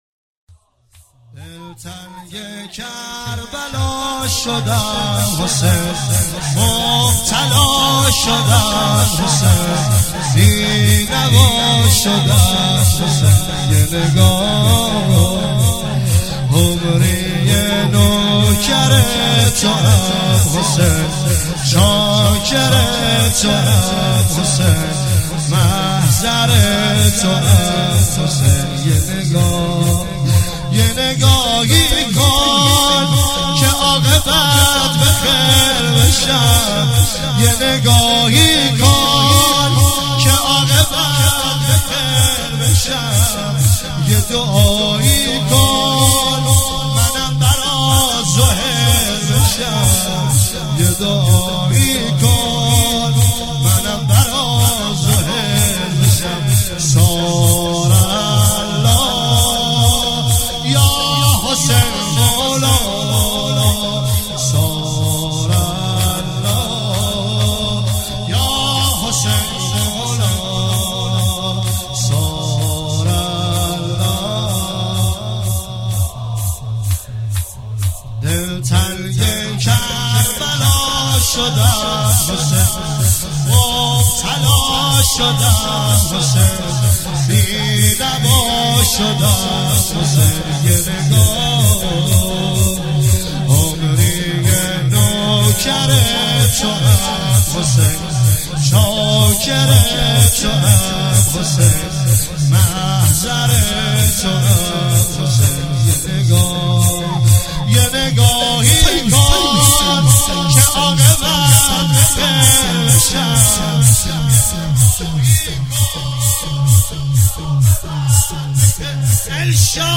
عنوان استقبال از ماه مبارک رمضان ۱۳۹۸
شور